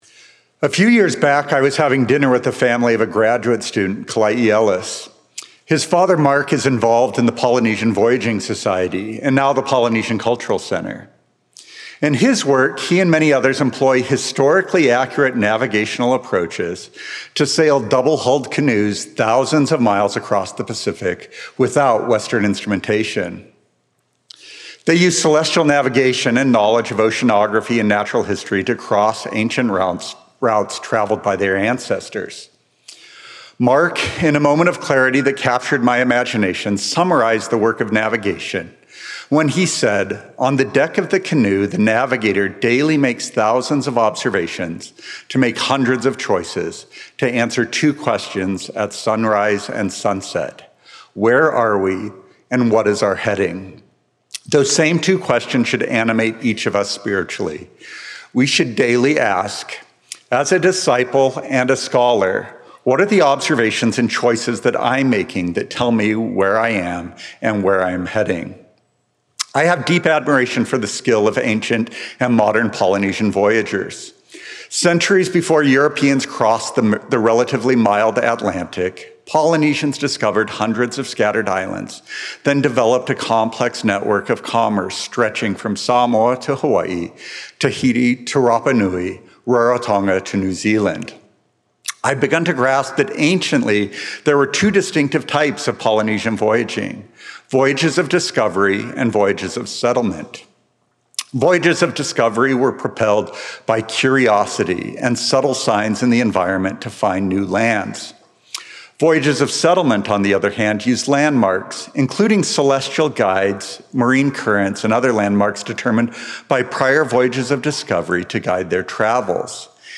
university conference 2017